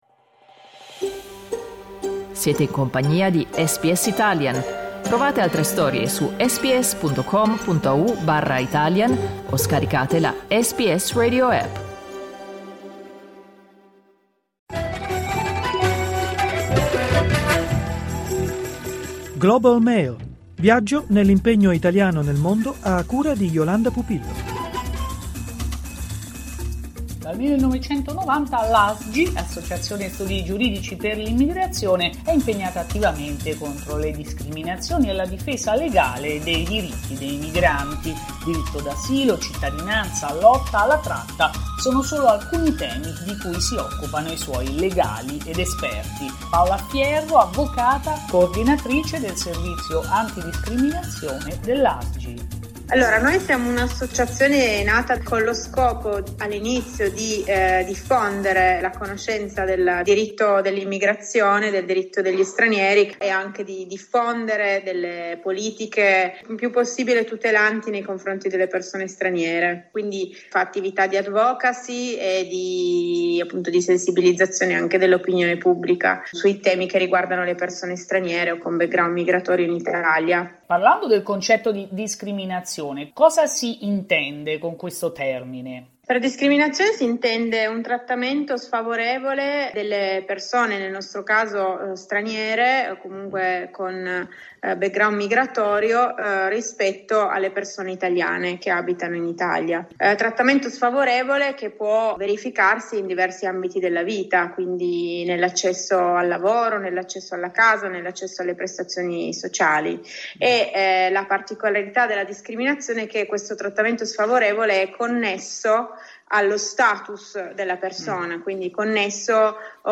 Ascolta l’intervista trasmessa da SBS Italian